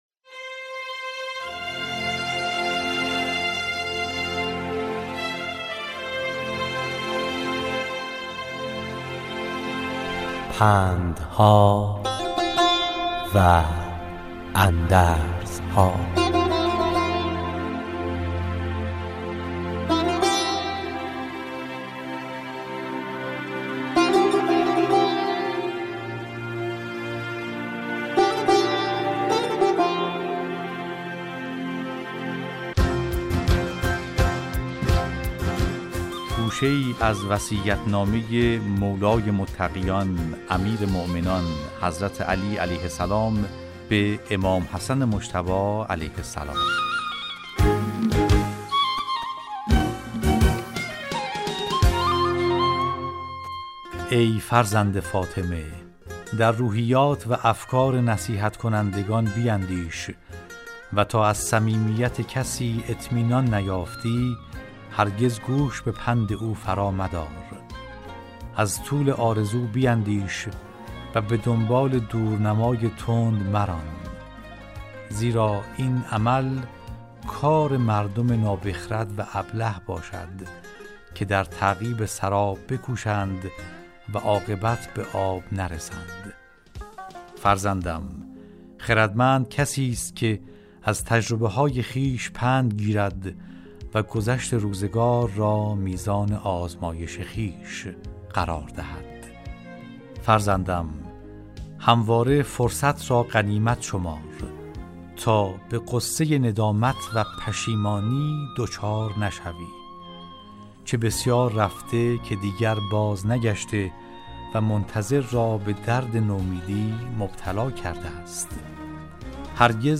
راوی برای شنوندگان عزیز صدای خراسان، حکایت های پندآموزی را روایت می کند .